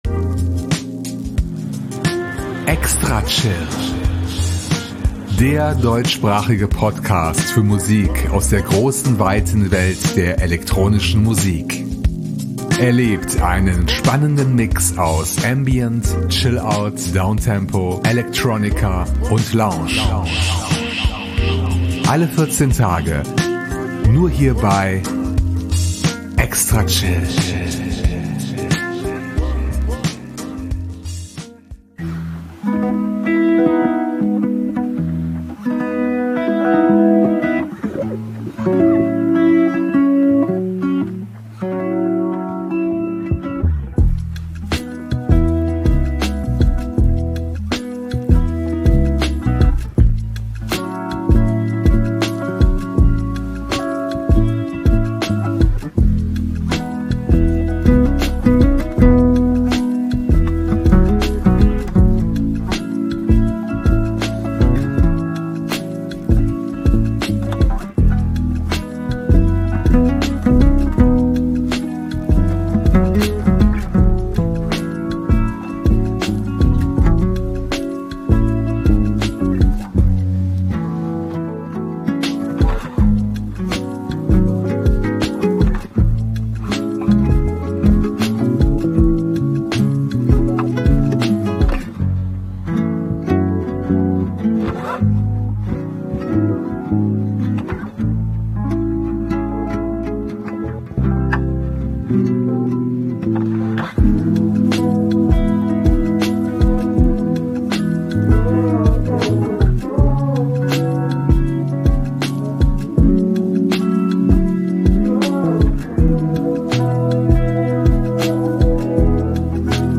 fresh, podsafe electronica